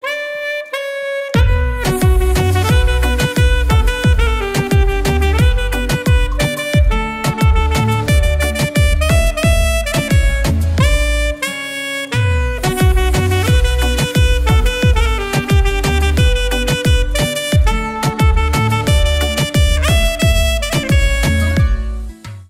Поп Музыка
без слов # кавер